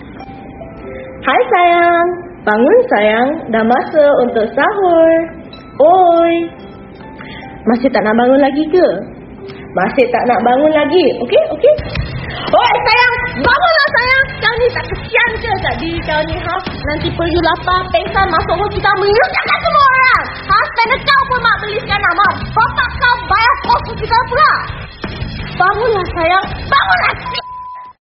Suara alarm Bangun Sayang (suara Cewek)
Kategori: Nada dering
Keterangan: Nada dering alarm Bangun Sayang adalah suara seorang gadis yang sangat lucu. Suara ini sangat cocok untuk dijadikan alarm sahur di bulan Ramadhan.
suara-alarm-bangun-sayang-suara-cewek-id-www_tiengdong_com.mp3